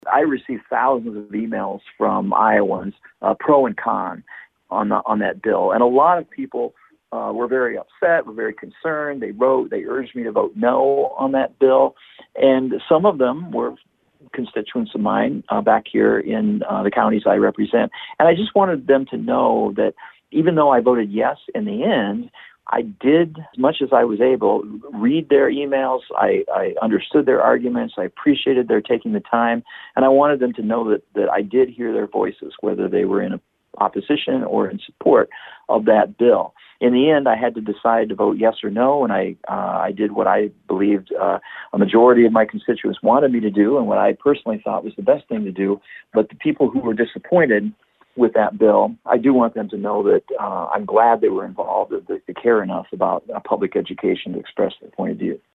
And that’s how District 2 State Senator Jeff Taylor of Sioux Center says a number of public schools in his district will actually GAIN funding from this bill.
He tells us how that can be.